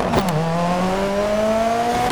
Index of /server/sound/vehicles/lwcars/delta